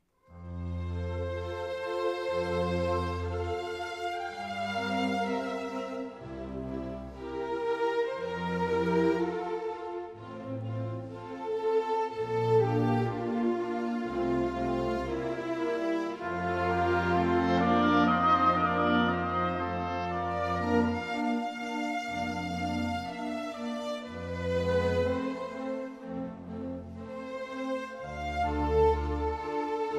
plays with a rich warmth and dark, richly burnished tone
Andante ma adagio 8.19